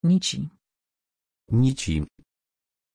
Aussprache von Nici
pronunciation-nici-pl.mp3